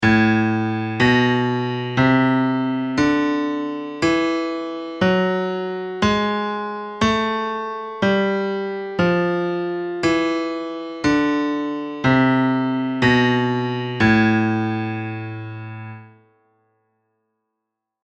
The principle seems strange, but when you hear it all together, the melodic minor sounds quite musical:
A Melodic Minor – the whole scale!
Therefore, the scale starts off sounding minor, and then switches to sounding major.
A-melodic-minor-whole-scale-again-1.mp3